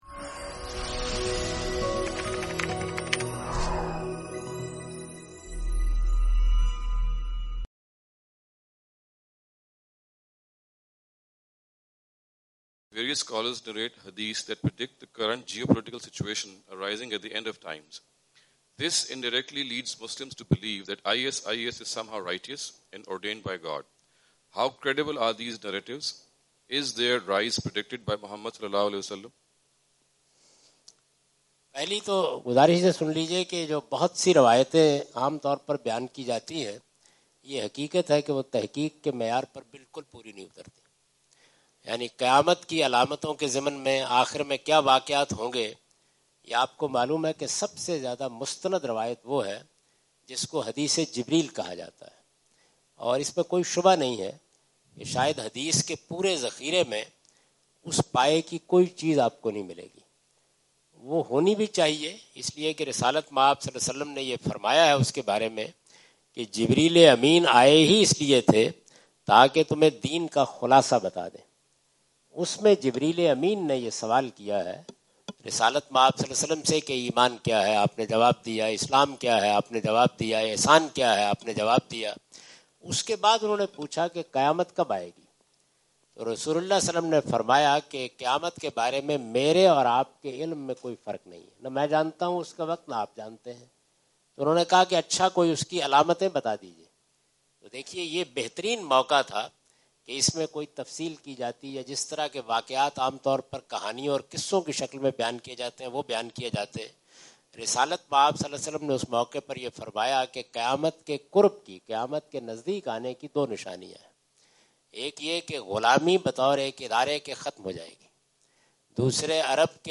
Javed Ahmad Ghamidi answer the question about "Predictions about Day of Judgment" during his US visit on June 13, 2015.
جاوید احمد غامدی اپنے دورہ امریکہ 2015 کے دوران سانتا کلارا، کیلیفورنیا میں "روزِ قیامت کی علامات" سے متعلق ایک سوال کا جواب دے رہے ہیں۔